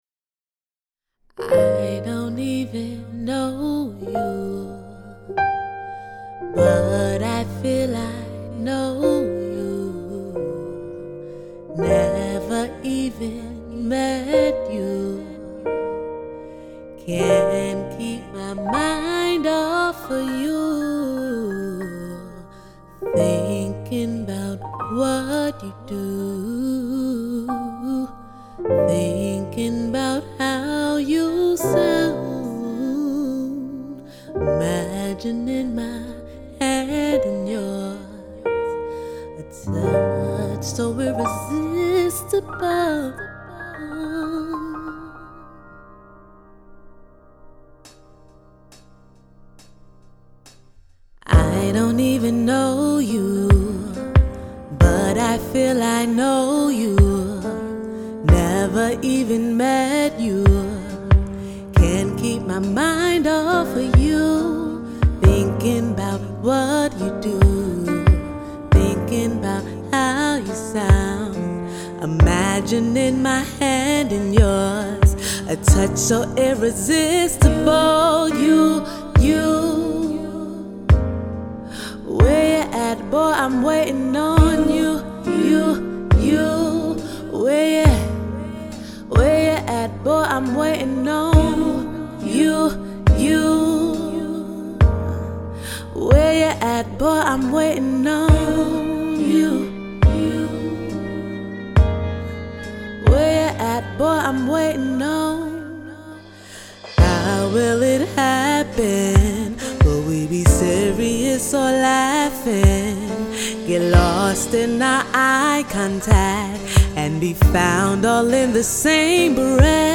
R&B singer/songwriter